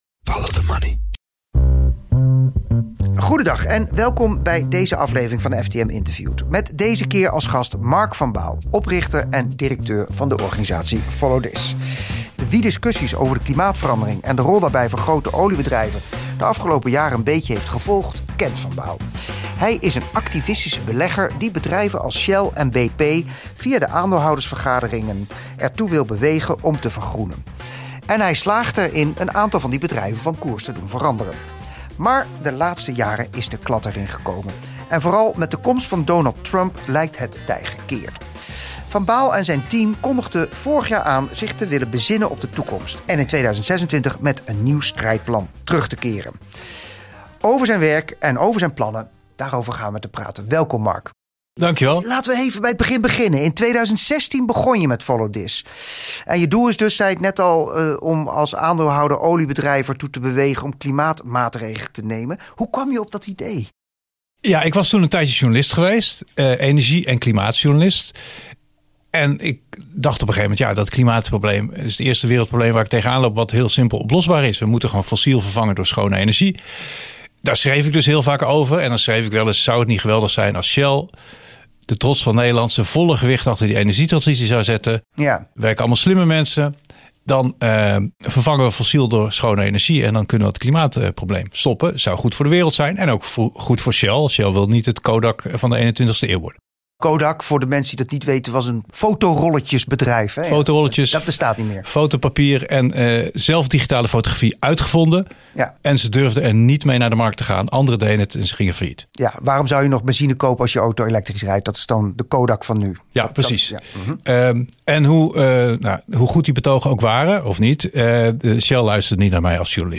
Follow the Money interviewt